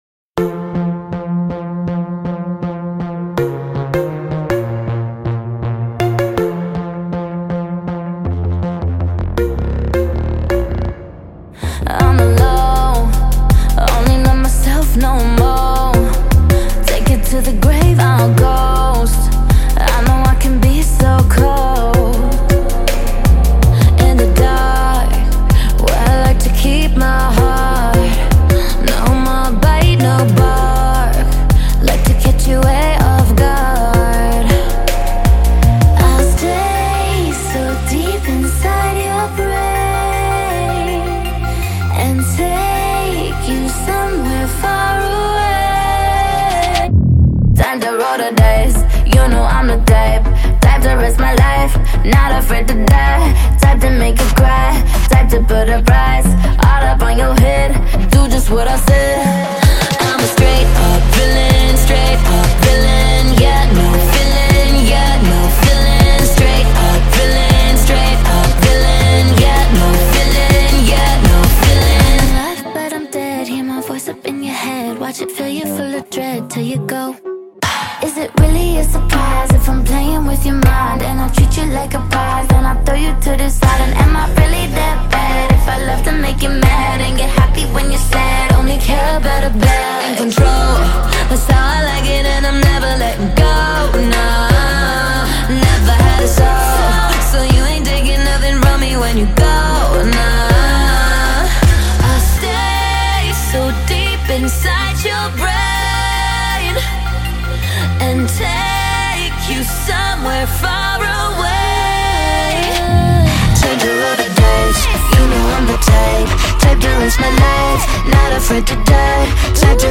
BPM40-80
MP3 QualityMusic Cut